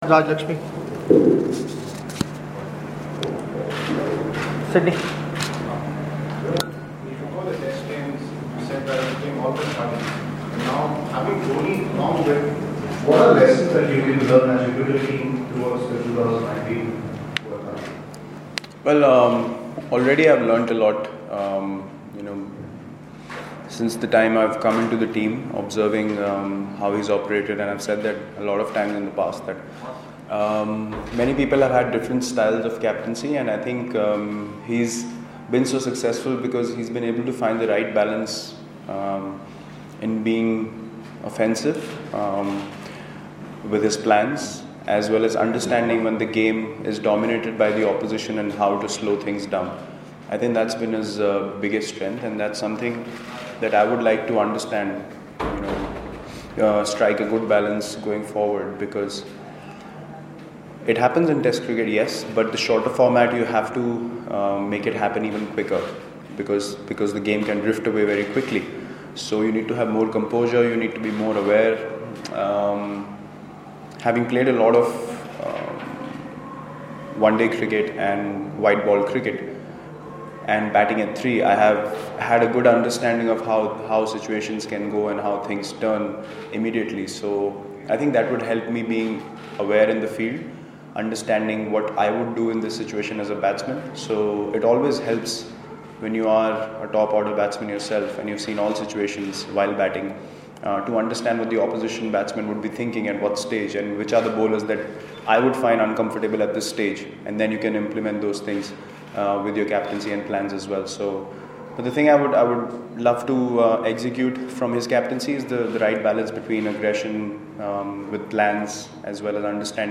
LISTEN: Skipper Virat Kohli speaks on the eve of Pune ODI